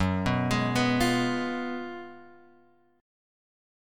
F# Major 7th Suspended 2nd Suspended 4th